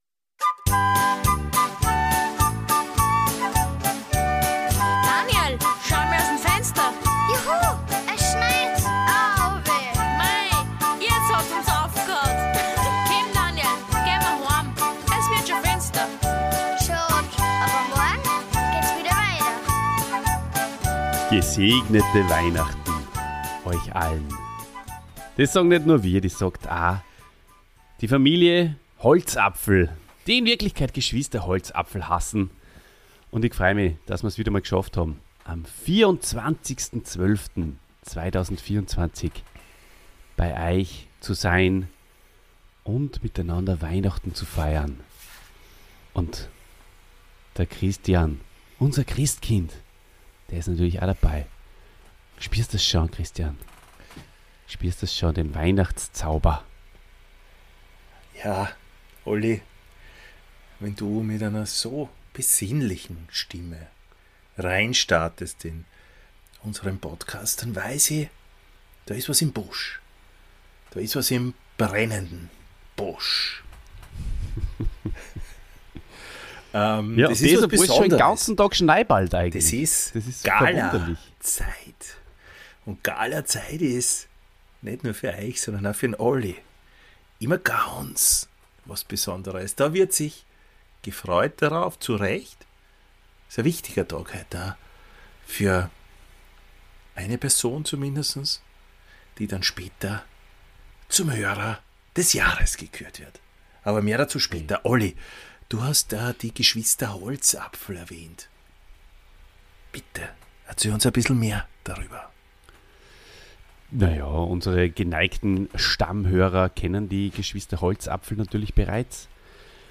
Infotainment!